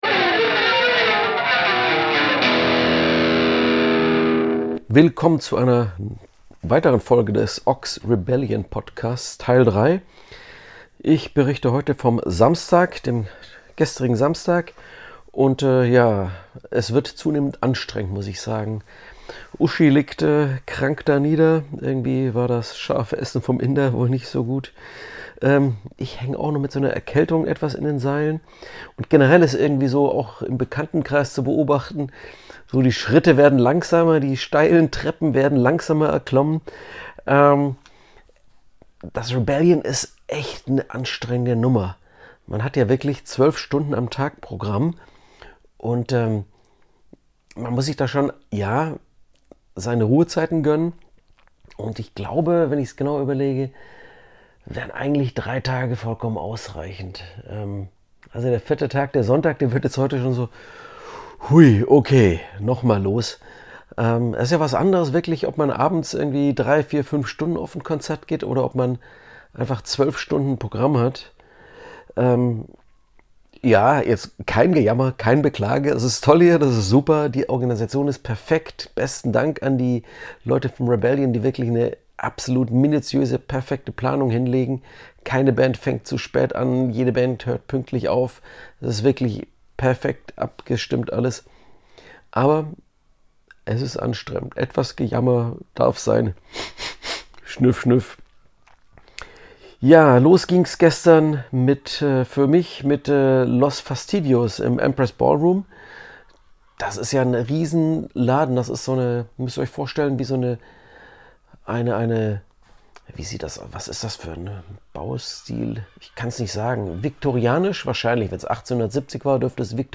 sind vor Ort und teilen mit euch jeden Tag ihre Eindrücke vom Vortag.